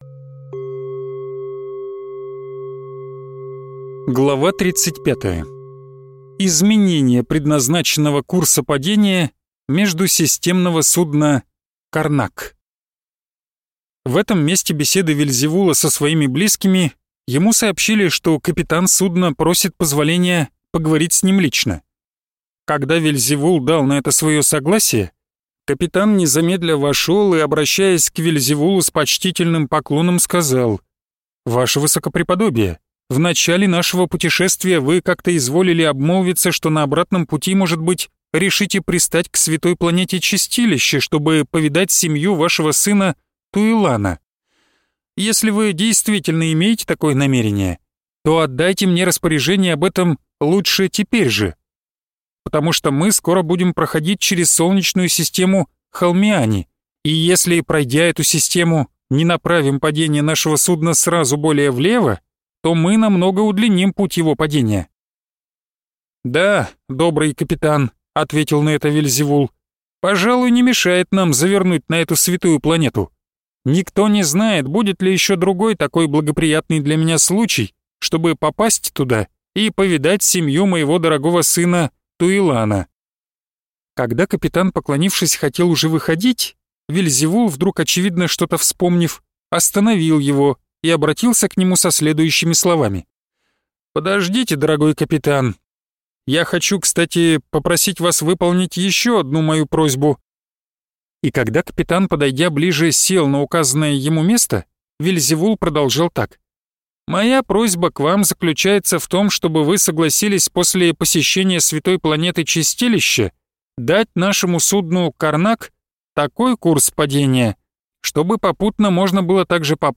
Аудиокнига Критика жизни человека. Рассказы Вельзевула своему внуку (Часть 3) | Библиотека аудиокниг